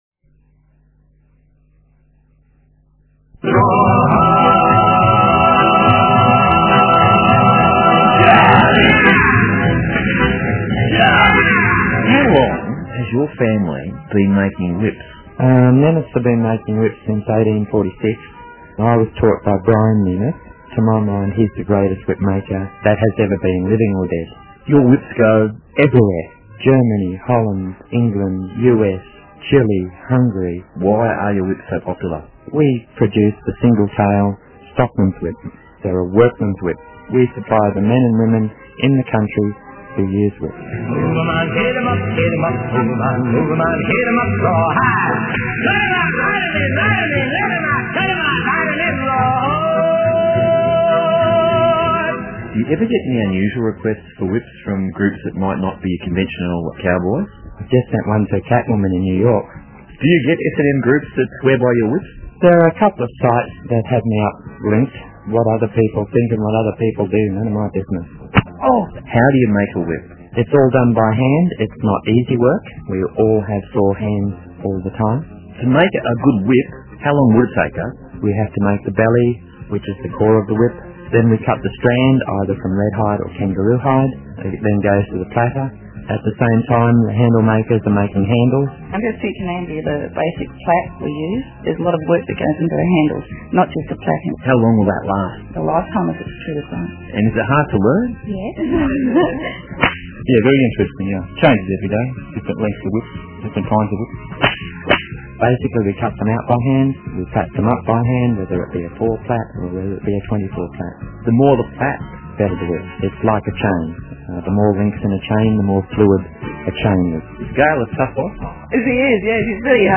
whipinterviewsm.mp3